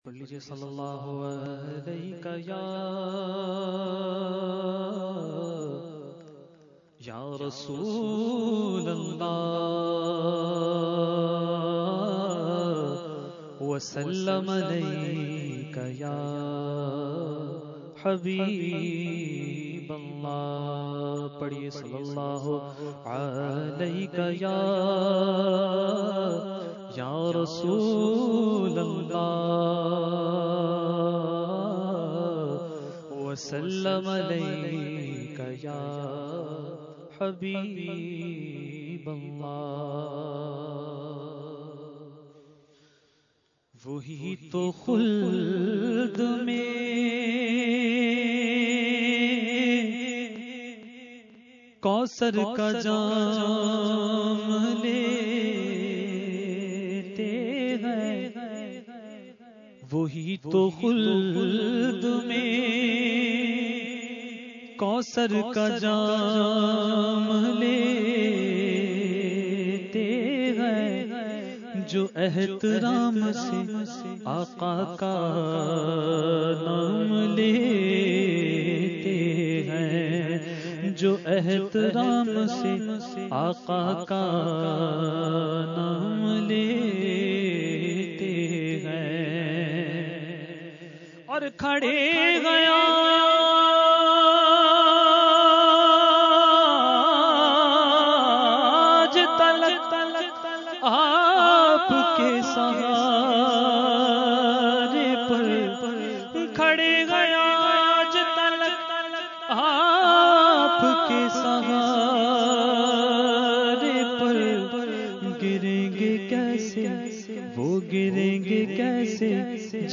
Category : Naat | Language : UrduEvent : Mehfil PECHS Society Khi 2015